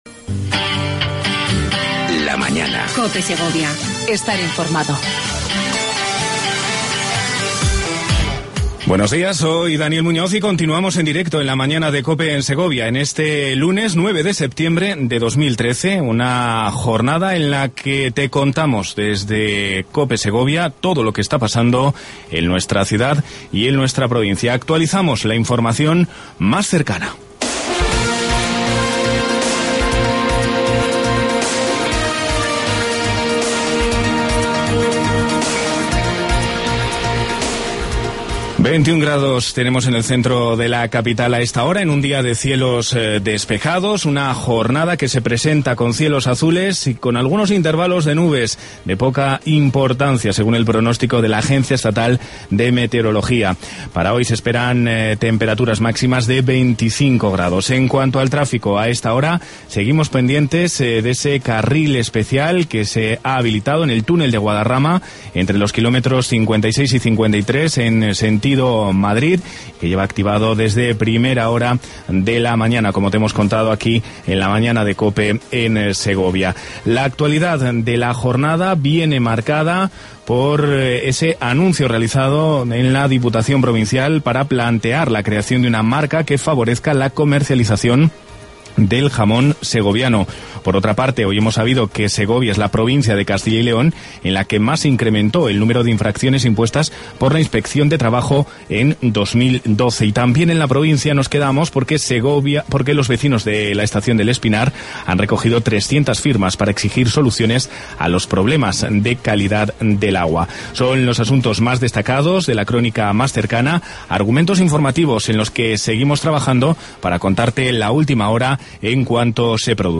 Redacción digital Madrid - Publicado el 09 sep 2013, 18:09 - Actualizado 14 mar 2023, 09:09 1 min lectura Descargar Facebook Twitter Whatsapp Telegram Enviar por email Copiar enlace El alcalde de Segovia, Pedro Arahuetes, repasa la actualidad municipal de las últimas semanas en su visita mensual a La Mañana de COPE en Segovia.